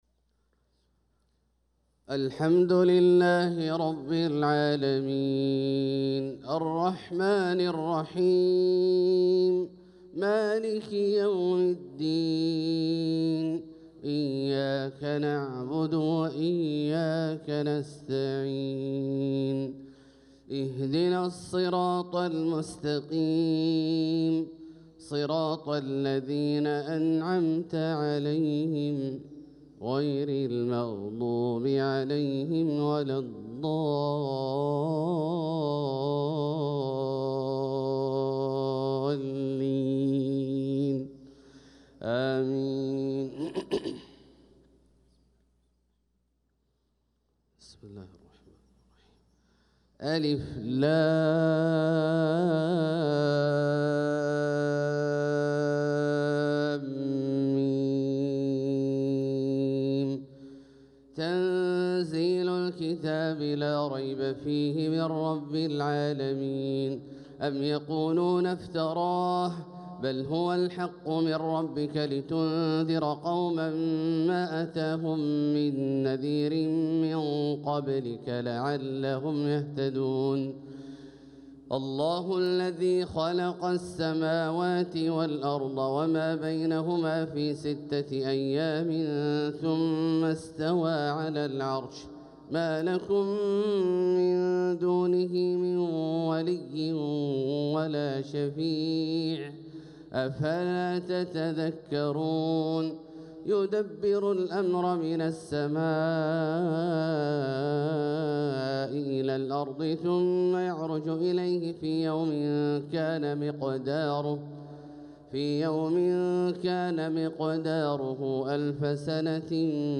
صلاة الفجر للقارئ عبدالله الجهني 26 صفر 1446 هـ
تِلَاوَات الْحَرَمَيْن .